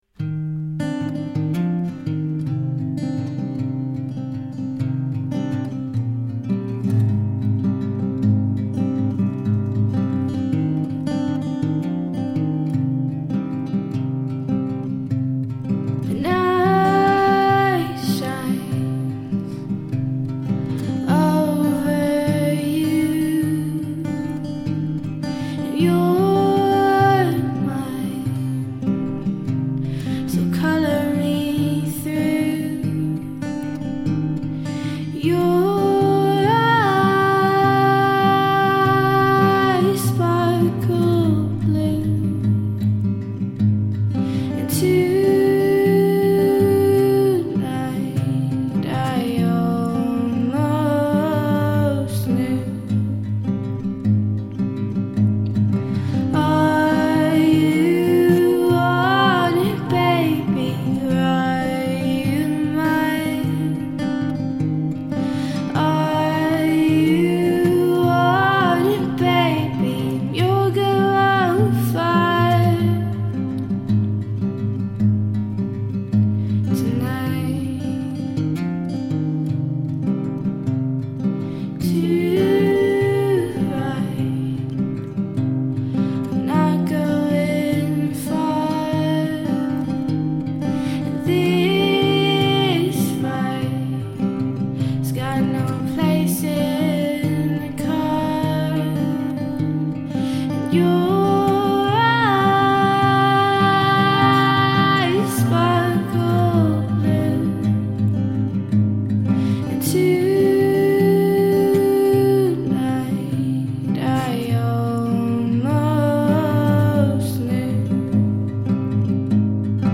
Alt-Folk